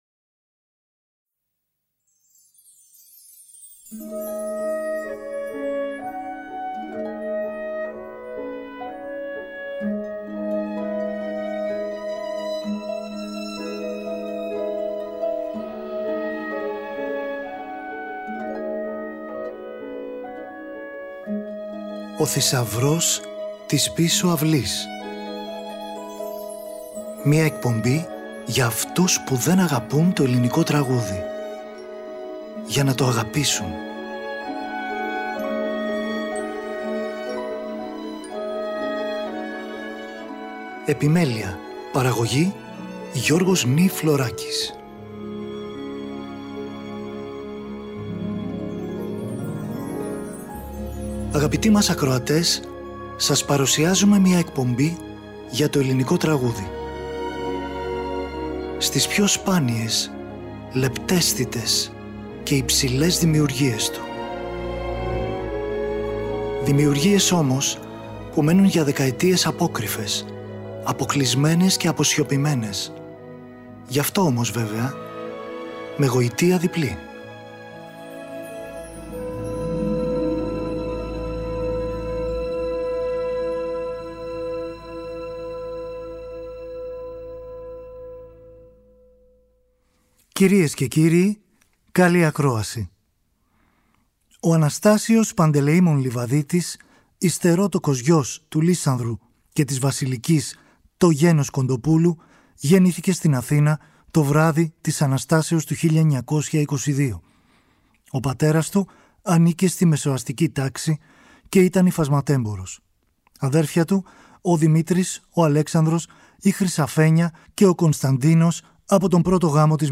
Σε αυτή ακούμε αποσπάσματα από το ορατόριο «Σκοτεινή Πράξη» του Μιχάλη Γρηγορίου σε ποίηση Τάσου Λειβαδίτη.